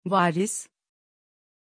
Pronunciation of Waris
Turkish
pronunciation-waris-tr.mp3